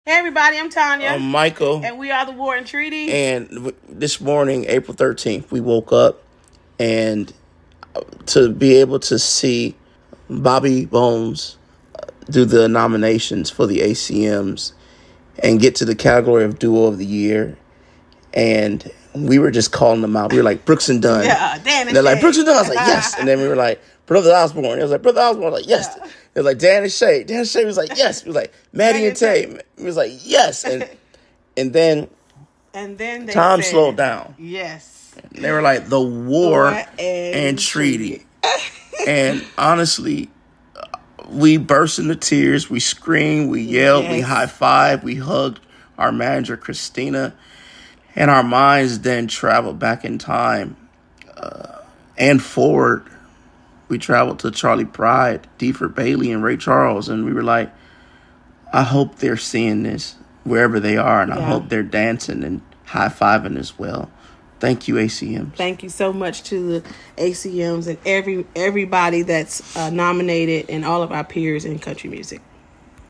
Audio / WHEN LAST YEAR'S ACM NOMINATIONS WERE ANNOUNCED, MICHAEL AND TANYA (pr. TONYA) TROTTER OF THE WAR AND TREATY TALK ABOUT THEIR FIRST ACM NOMINATION FOR DUO OF THE YEAR.